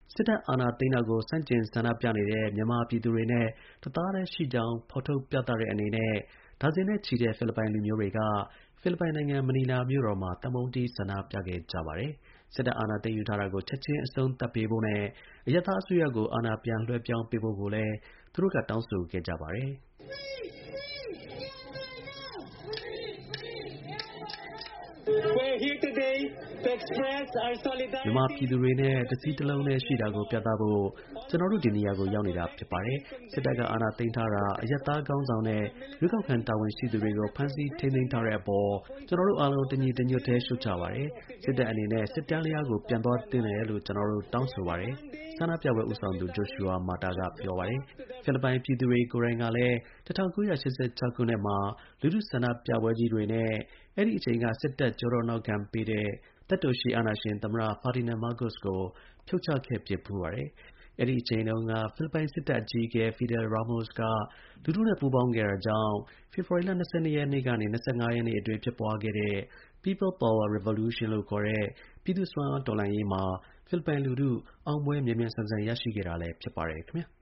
မြန်မာစစ်တပ်အာဏာသိမ်းတာကို ဆန့်ကျင်ဆန္ဒပြနေတဲ့ မြန်မာပြည်သူတွေနဲ့ တသားတည်း ရှိနေကြောင်း ဒါဇင်နဲ့ ချီတဲ့ ဖိလစ်ပိုင် လူမျိုးတွေက ဖိလစ်ပိုင်နိုင်ငံ မနီလာမြို့မှာ သံပုံးတီး ဆန္ဒပြခဲ့ပါတယ်။